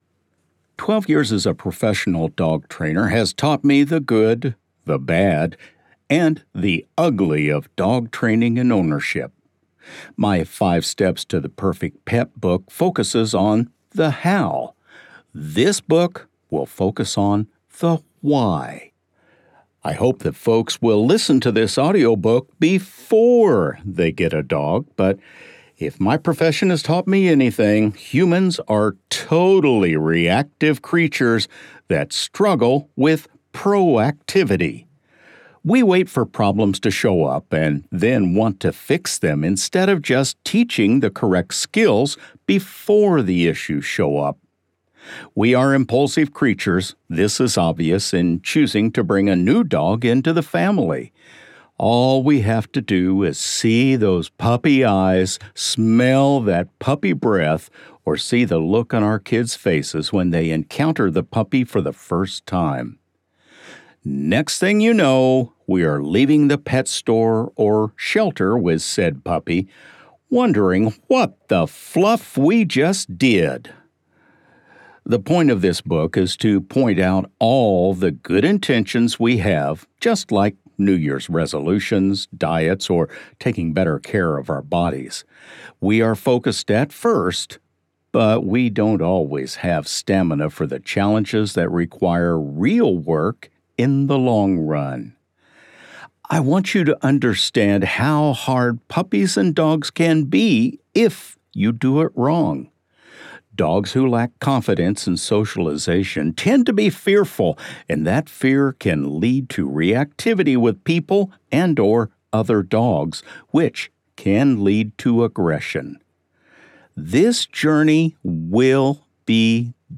So You Want A Dog WTF? – Audio Book